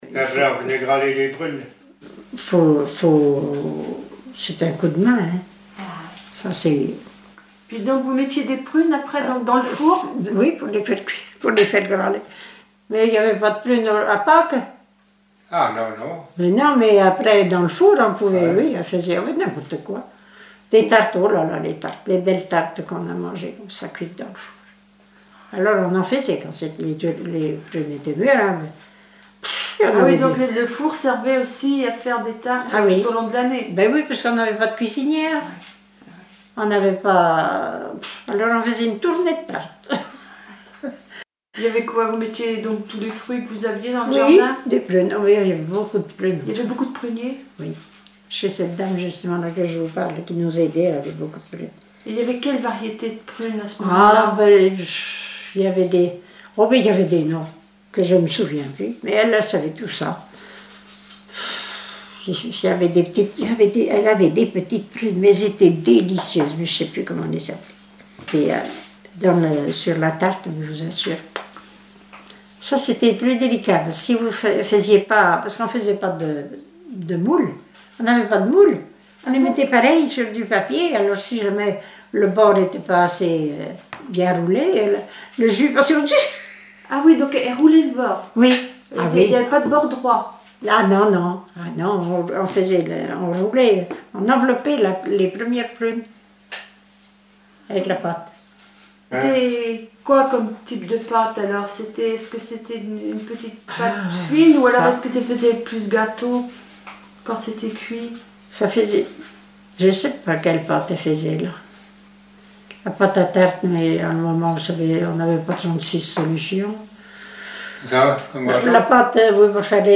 Témoignages sur les tâches ménagères
Catégorie Témoignage